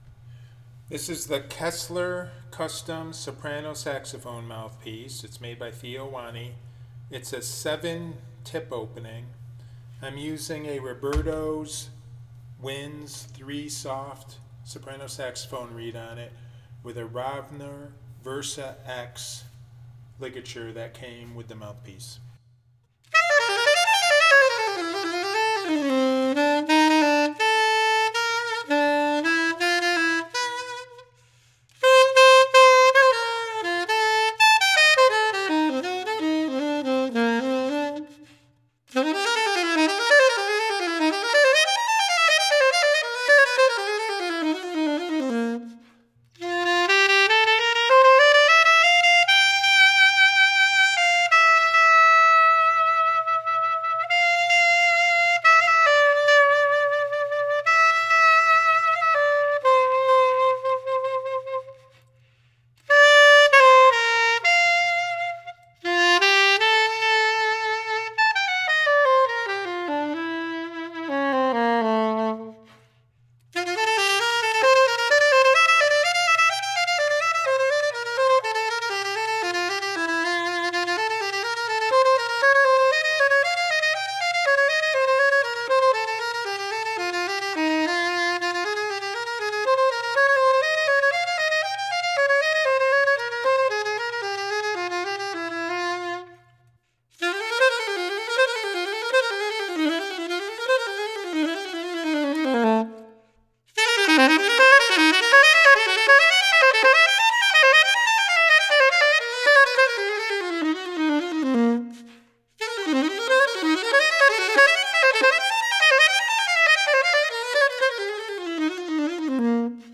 The articulation was clean and crisp and the Kessler Custom 7 soprano saxophone mouthpiece performed well on fast jazz lines while applying articulation.
The first sound clip is recorded with some reverb added on a Roberto’s Winds 3 Soft soprano saxophone reed.
The reverb seems to fatten up the natural brightness of the soprano saxophone.
In my opinion, the Kessler Custom 7 soprano saxophone mouthpiece is a fabulous budget friendly soprano saxophone mouthpiece for those looking for a large chambered soprano sax mouthpiece that delivers a medium bright and powerful room-filling tone that sounds big, full and fat sounding.
Kessler Custom 7 Soprano Saxophone Mouthpiece by Theo Wanne – Reverb Added-Robertos Winds 3 Soft Soprano Saxophone Reed – Rovner Versa-X Ligature